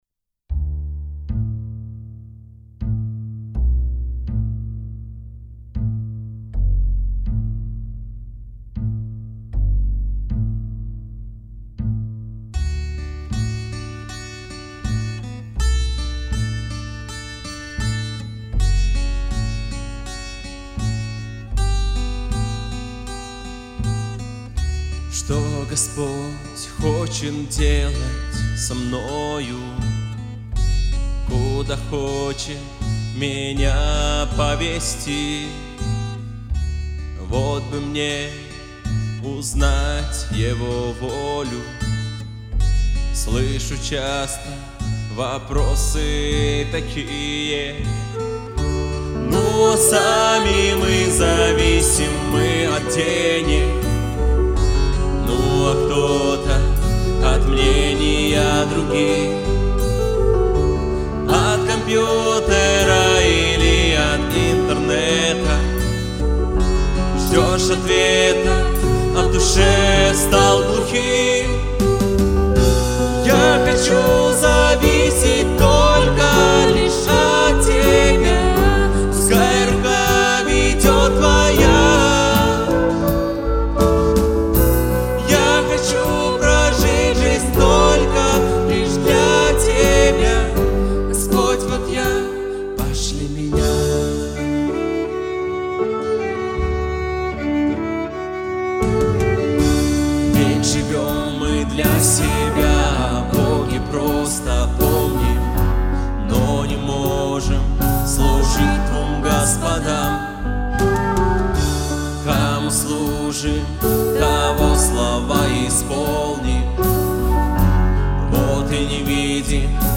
песня
298 просмотров 108 прослушиваний 10 скачиваний BPM: 80